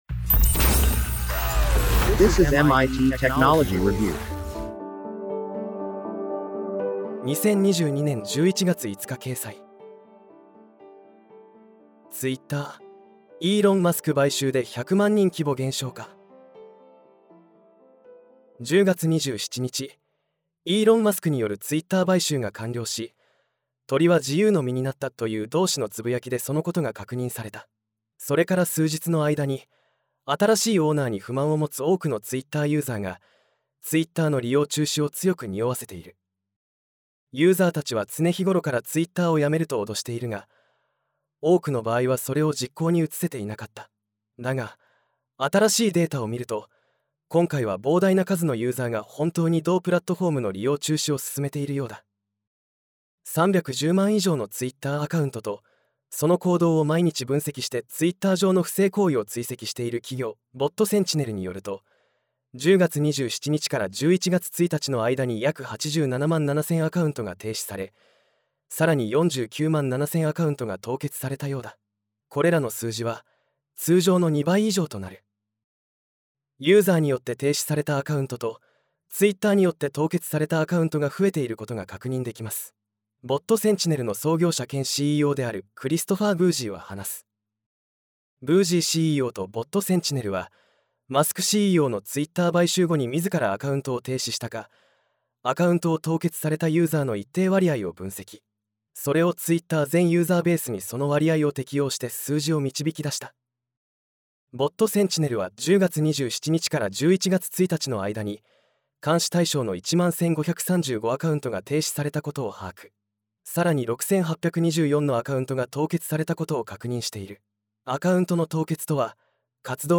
なお、本コンテンツは音声合成技術で作成しているため、一部お聞き苦しい点があります。